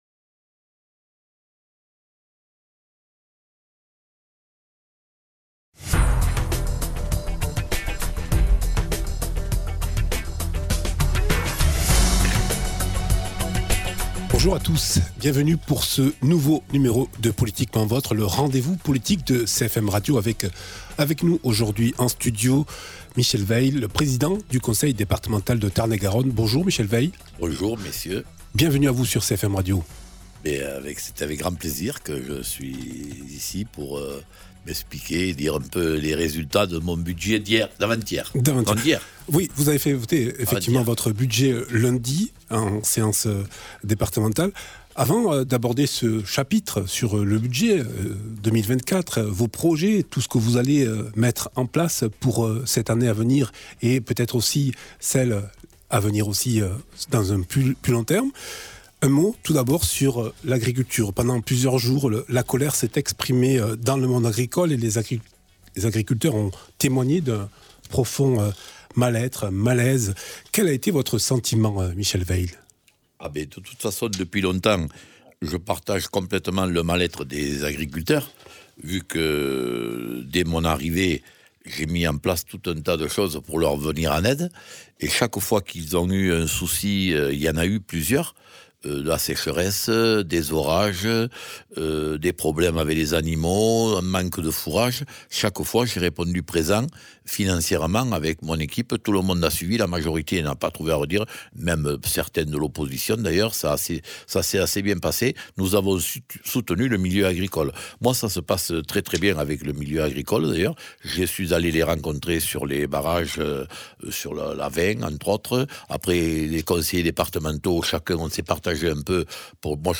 Michel WEILL, président du conseil départemental de Tarn-et-Garonne était l’invité de politiquement votre. L’occasion d’évoquer les manifestations des agriculteurs, la problématique de l’eau et de la transition énergétique. À quelques jours du vote du budget 2024, Michel WEILL à présenté les projets du Conseil Départemental.